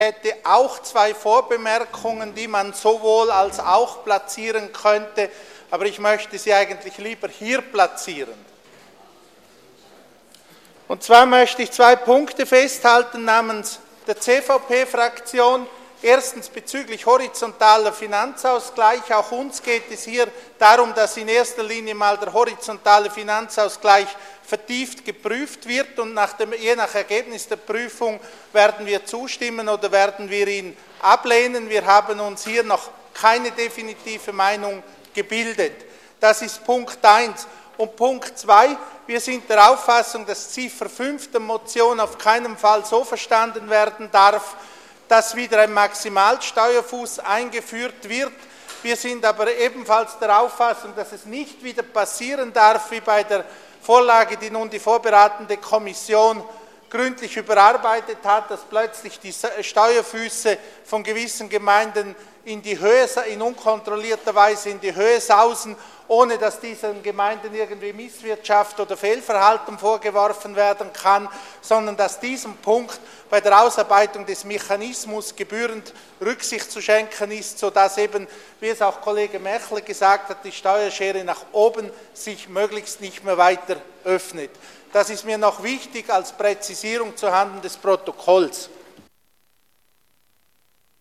25.9.2012Wortmeldung
Session des Kantonsrates vom 24. und 25. September 2012